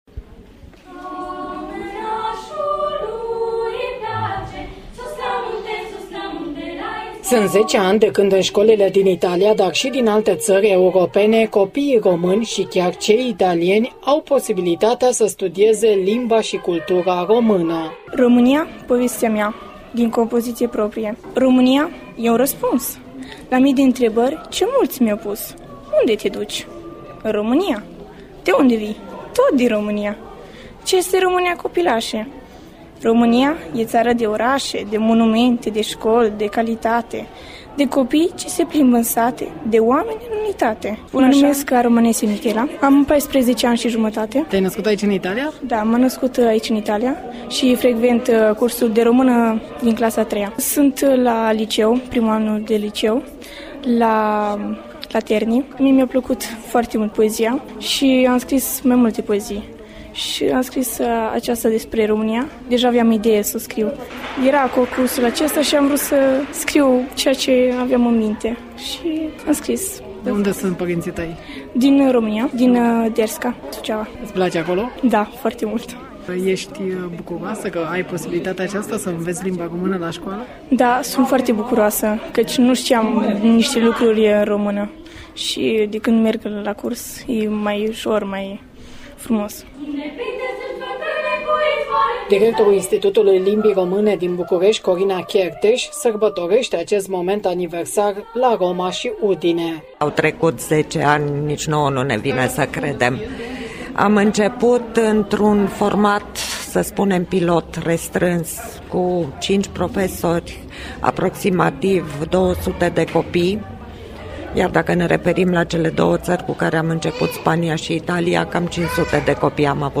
Împlinirea celor 10 ani  a fost marcată la Roma si Udine de profesori români și italieni, elevi români și italieni prin poezii, desene, muzică și dansuri tradiționale.